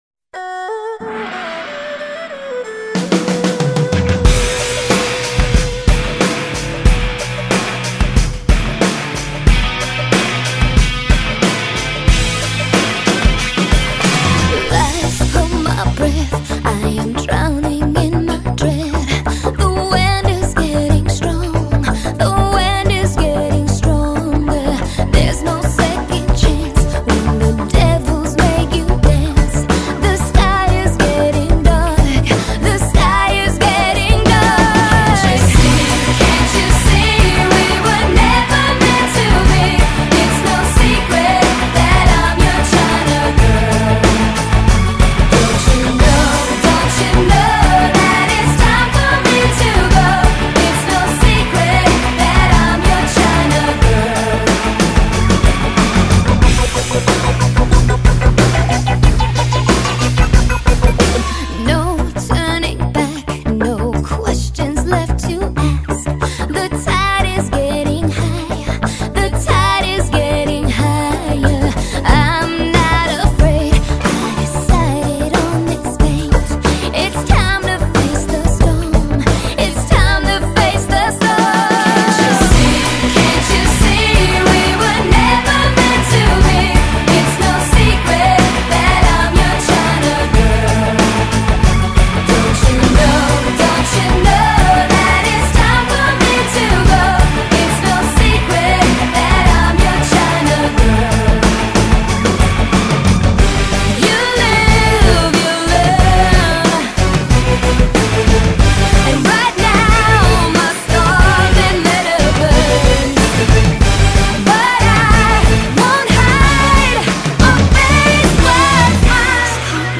★全碟收录英文版原主唱